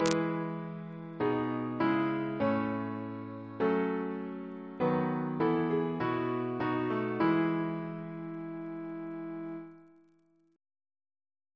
Single chant in E Composer: Benjamin Cooke (1734-1793) Reference psalters: ACB: 86; ACP: 254; OCB: 91